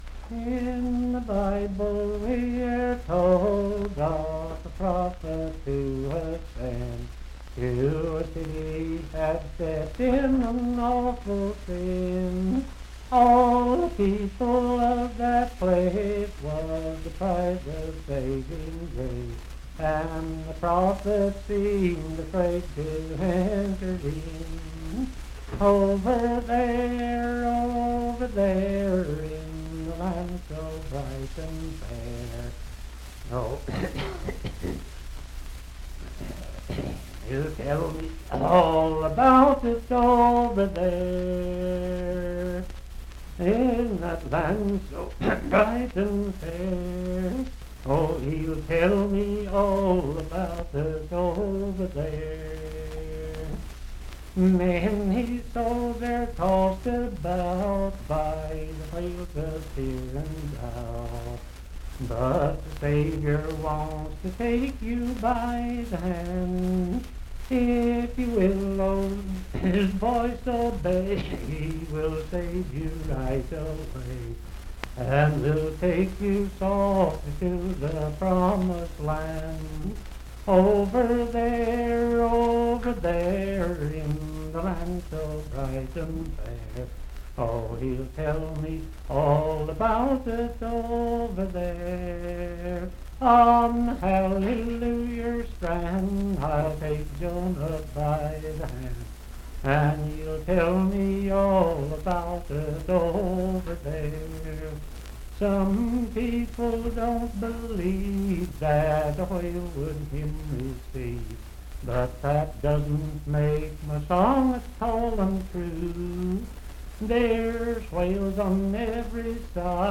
Unaccompanied vocal music
in Dryfork, WV.
Verse-refrain 4d(4) & Rd(4).
Hymns and Spiritual Music
Voice (sung)
Randolph County (W. Va.)